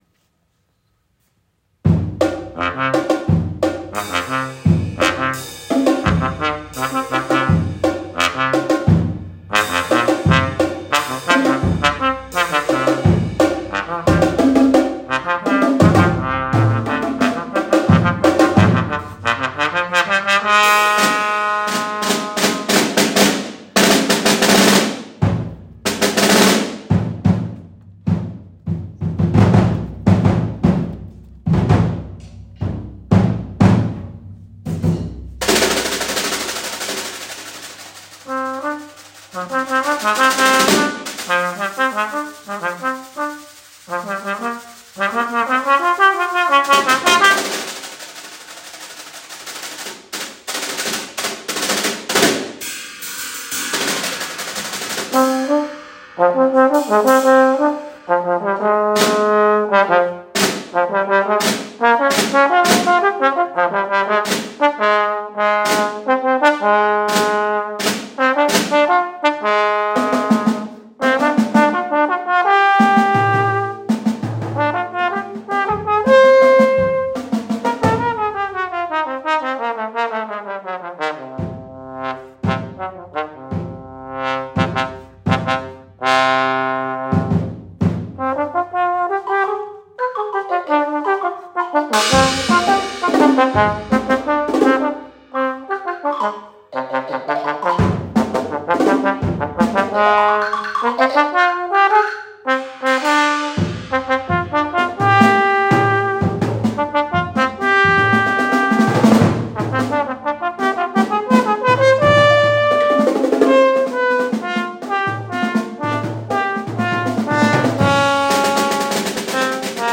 For trombone and percussion, 8’20